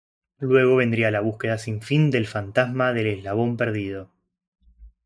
fan‧tas‧ma
Pronounced as (IPA)
/fanˈtasma/